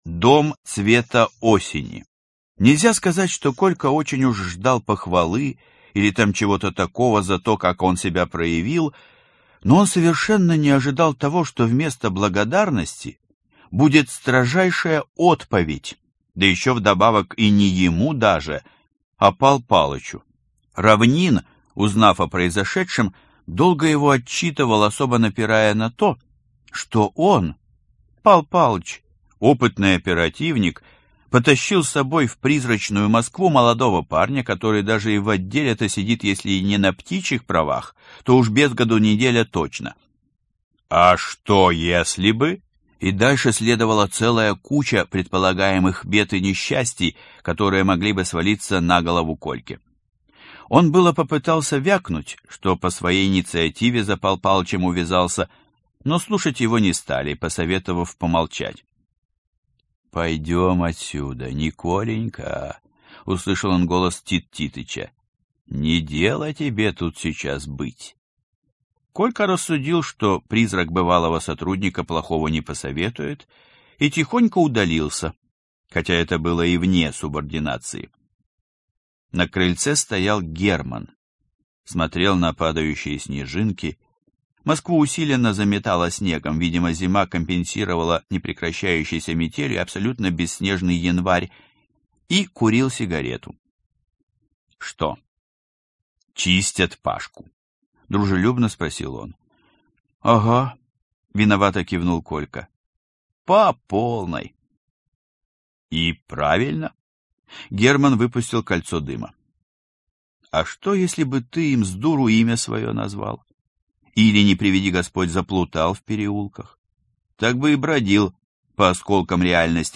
Аудиокнига Отдел «15-К». Сезон 1. Выпуск 2 | Библиотека аудиокниг